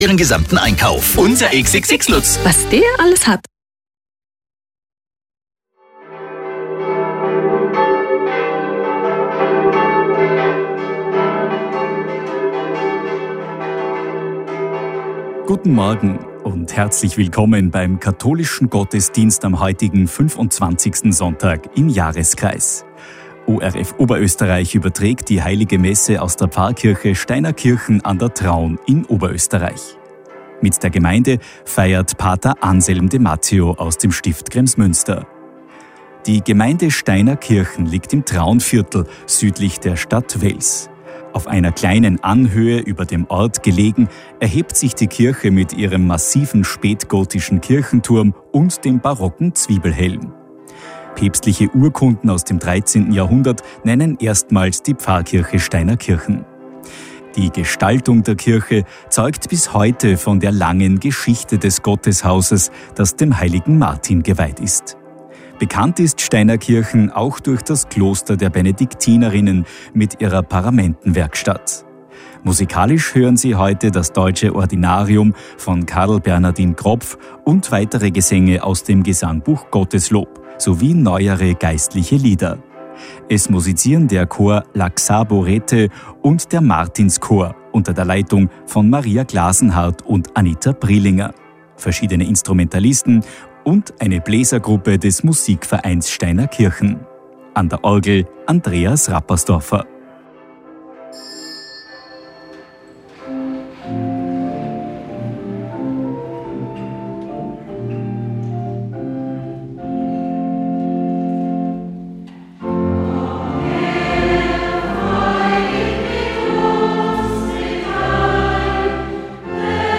Radiogottesdienst am 21.09. in Steinerkirchen
ORF OÖ hat den Sonntagsgottesdienst aus der Pfarre Steinerkirchen in OÖ live übertragen, den
Musikalisch haben Sie das Deutsche Ordinarium von Karl-Bernhardin Kropf, und weitere Gesänge aus dem Gesangbuch GOTTESLOB, sowie neuere geistliche Lieder gehört. Es haben der Chor Laxabo Rete und der Martins-Chor unter der Leitung von